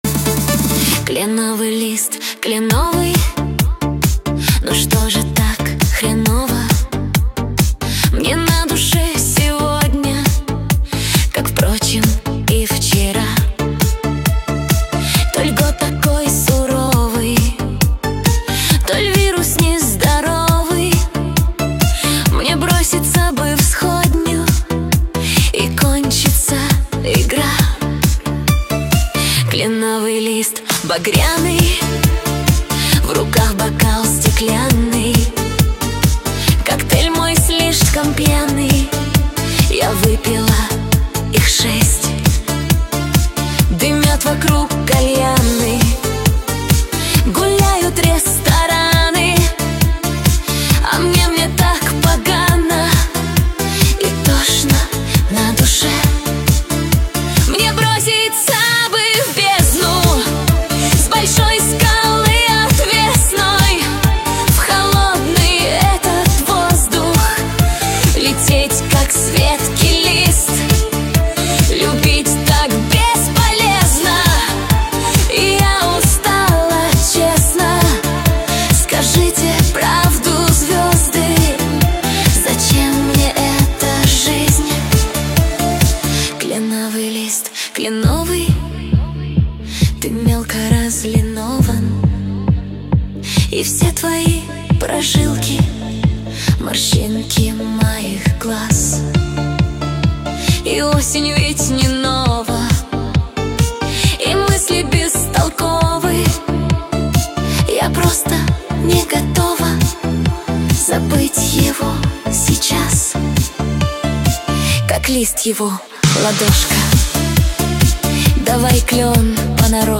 Качество: 235 kbps, stereo